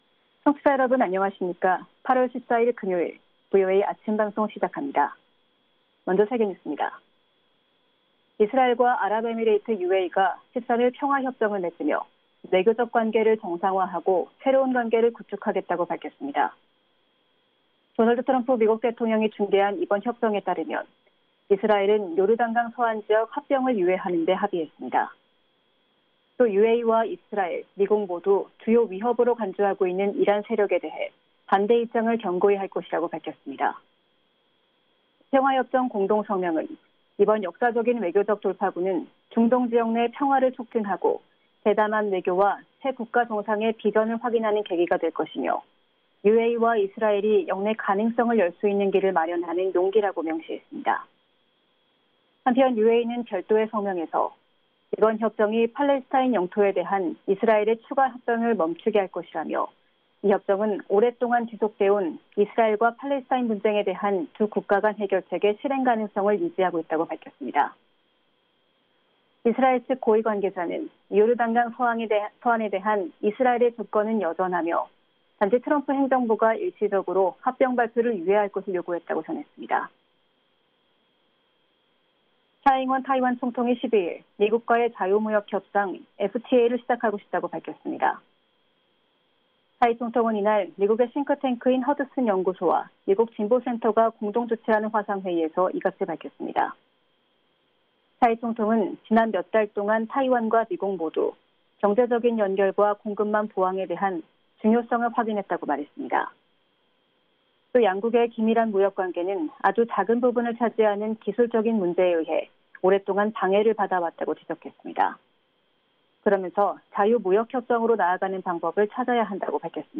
VOA 한국어 아침 뉴스 프로그램 '워싱턴 뉴스 광장' 2020년 8월 14일 방송입니다. 코로나 바이러스 감염증 2차 대유행 우려로 연기됐던 미-한 연합훈련이 오늘(18일) 시작됩니다.